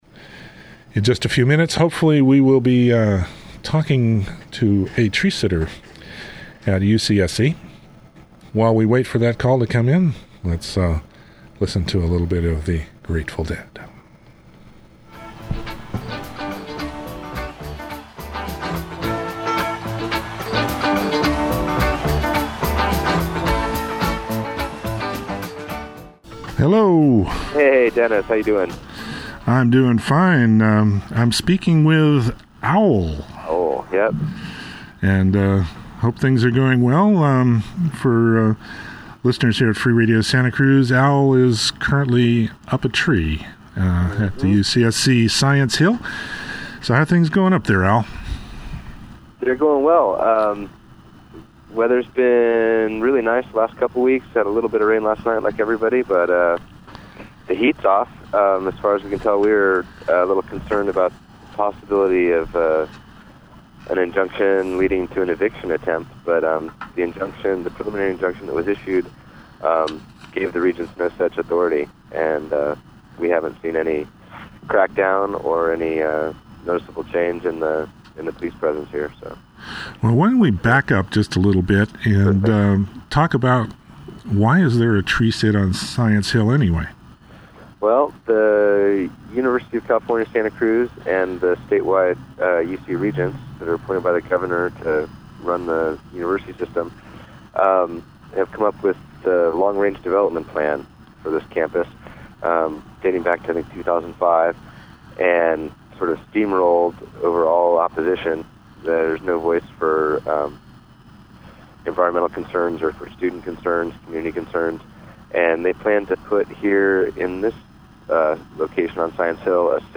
Earth First! Radio Special broadcast
Includes call-in questions and comments from Free Radio Santa Cruz listeners.